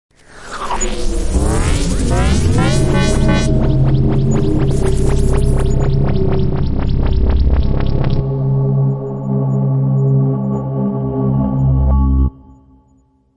变形的声音效果 28
描述：从ZOOM H6录音机和麦克风Oktava MK01201的现场录制的效果，然后进行处理。
Tag: 未来 托管架 无人驾驶飞机 金属制品 金属 过渡 变形 可怕 破坏 背景 游戏 黑暗 电影 上升 恐怖 开口 命中 噪声 转化 科幻 变压器 冲击 移动时 毛刺 woosh 抽象的 气氛